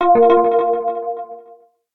Ding 1
coin ding ring sound effect free sound royalty free Sound Effects